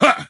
bo_fire_vo_01.ogg